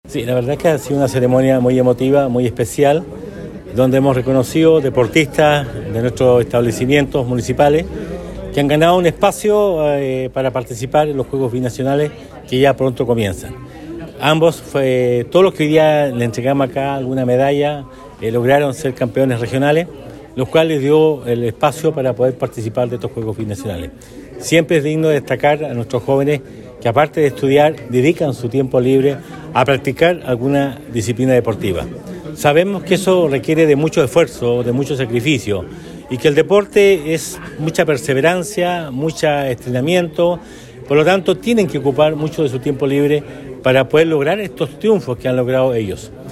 En una significativa ceremonia, donde participó el alcalde Emeterio Carrillo, junto a los concejales Miguel Arredondo; Mario Troncoso y Juan Carlos Velásquez, reconocieron a 14 jóvenes deportistas que representarán a la educación pública de nuestra comuna en el próximo Campeonato Nacional de los Juegos Deportivos Escolares, a realizarse en Concepción en septiembre próximo.
El Alcalde Emeterio Carrillo señaló que este es un incentivo para los jóvenes que participaran de los Juegos Binacionales, ya que deben ocupar su tiempo libre para perfeccionarse en sus disciplinas deportivas.